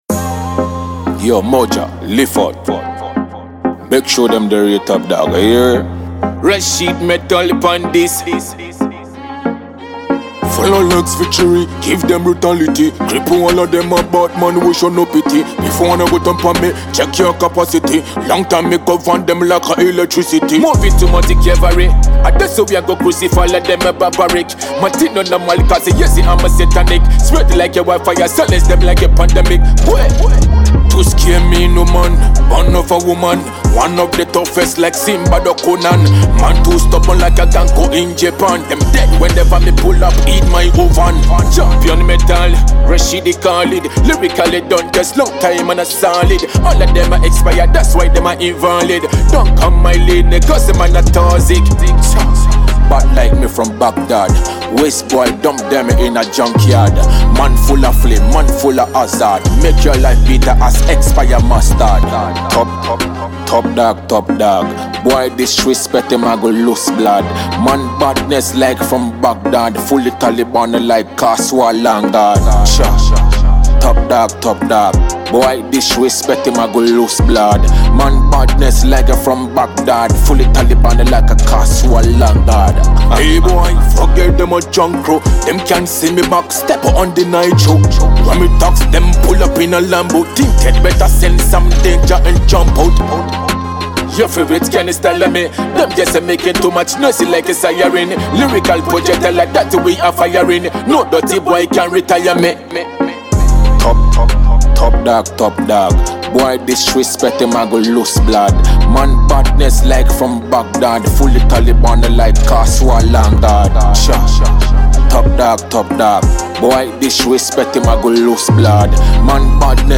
a Ghanaian Afro artist